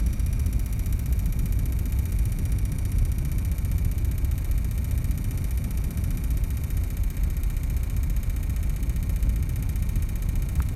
Problème bruit clim split intérieur Mitsubishi
Comment mettre un son ici, il me dit fichier incorrect, je tiens à vous remercier tous pour vos réponses, il me tarde de résoudre ce bruit de raclette, je précise que ça ne le fait qu'en mode chauffage dès qu'il y a de la chaleur (tic tic tic tic tic tic).
Voilà le bruit de la clim.
Waaah, ça fait Kalachnikov pour bébé, votre truc, je comprends tout à fait que ça vous énerve!